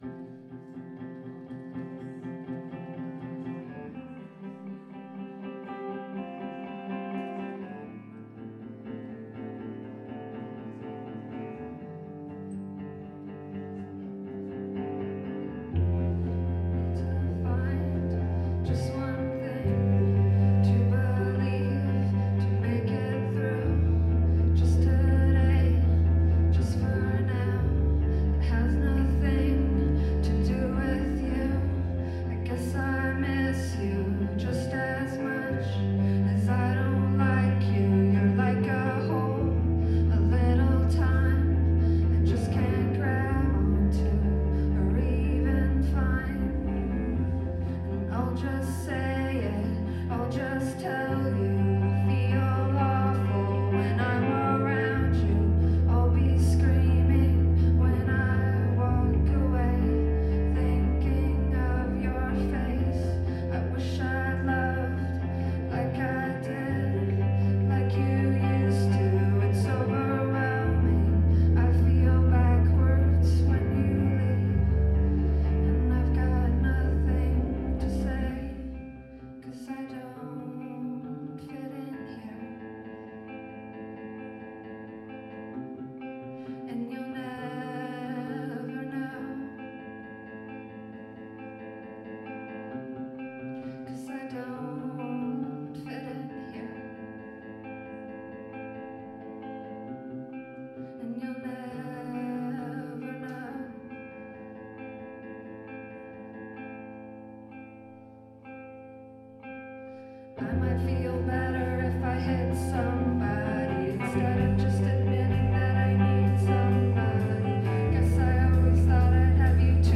bass
drums
2006-09-09 The Paradox Theatre – Seattle, WA